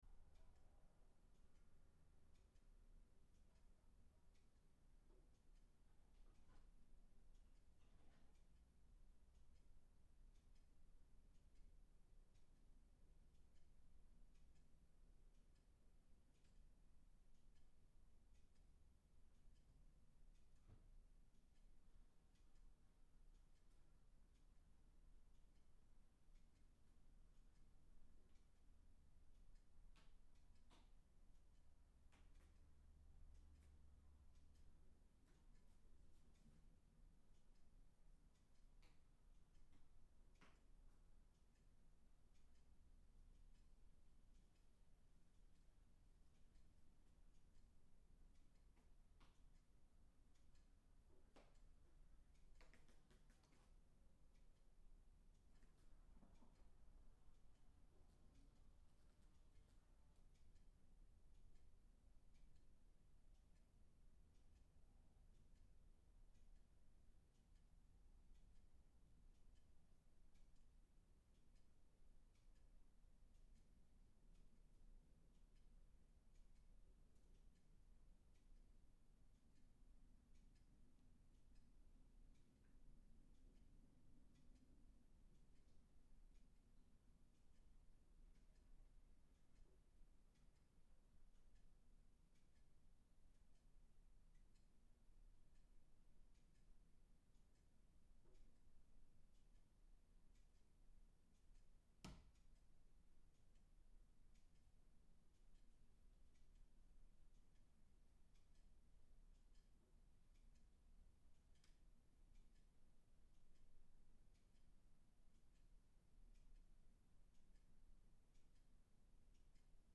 Large vs small diaphragm condenser microphone for nature recordings.
This recordings was made in 50m2 garage in the countryside. This recording contains mainly two ticking clocks, both sides of the mic rig, also a buzzing fly and a mouse jumping somewhere in the garage. Outside is a traffic in a distance
First three audio samples are straight from the recorder at 50dB gain, so it sounds in very low level. *
Sennheier MKH8040